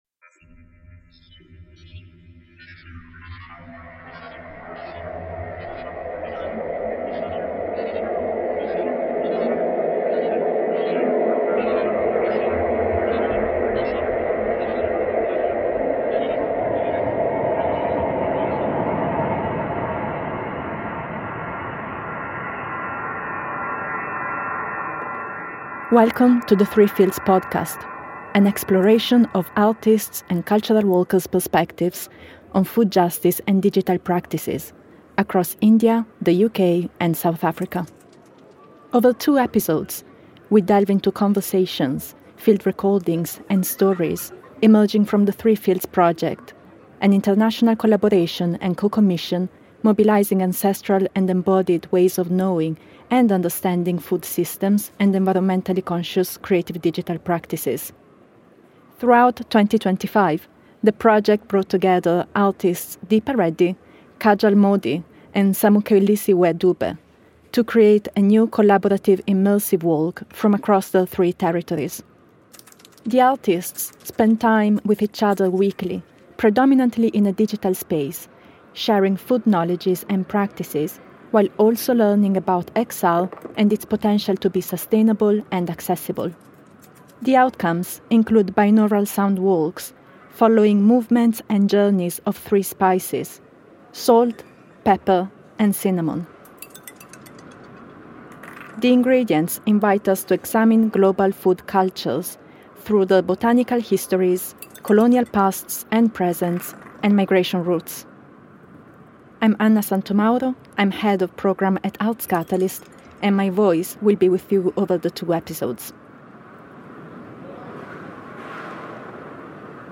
Over 2 episodes, we delve into conversations, field recordings, and stories emerging from the THREE FIELDS project; an international collaboration and co-commission between Abandon Normal Devices, Arts Catalyst, Fak’ugesi, Fast Familiar, and Unbox Cultural Futures.